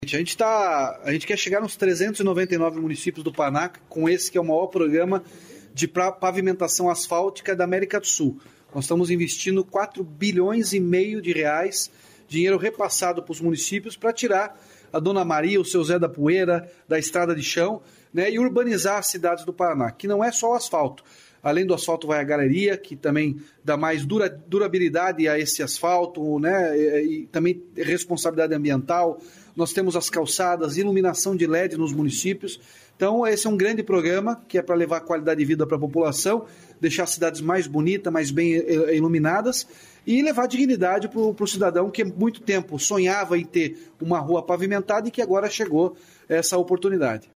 Sonora do governador Ratinho Junior sobre o programa Asfalto Novo, Vida Nova